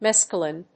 音節mes・ca・lin 発音記号・読み方
/méskəlɪn(米国英語)/